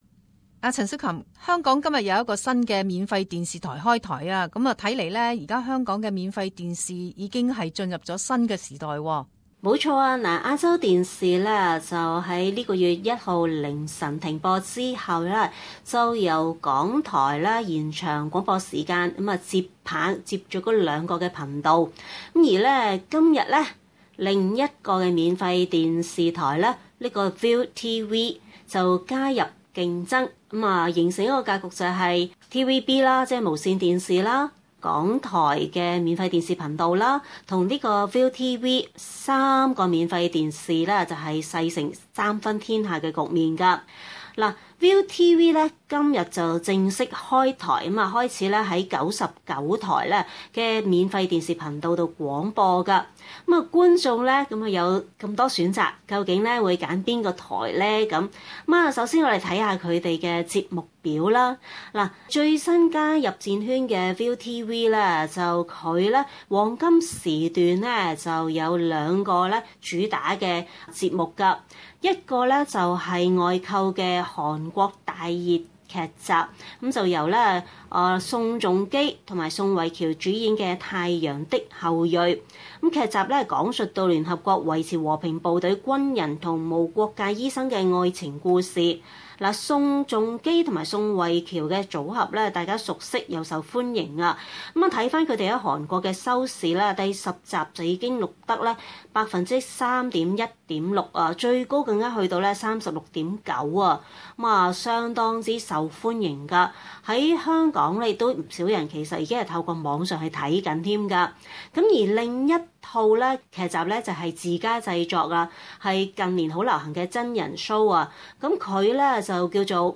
中港快讯